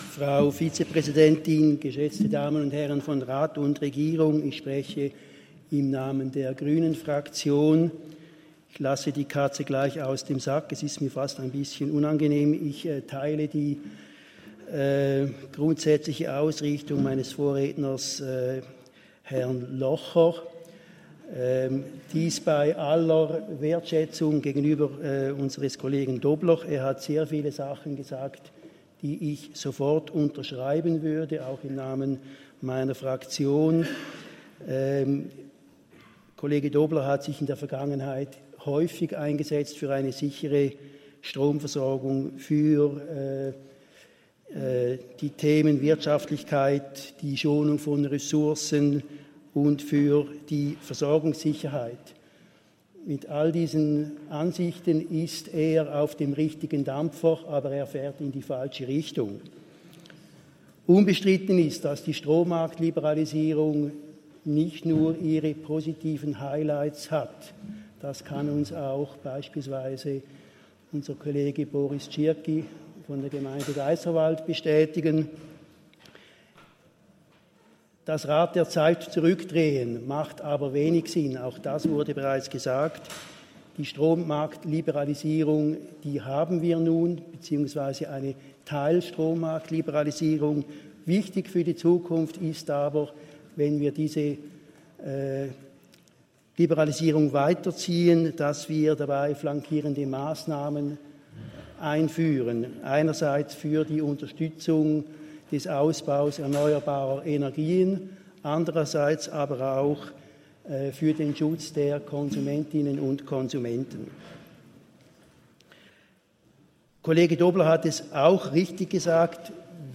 Session des Kantonsrates vom 18. bis 20. September 2023, Herbstsession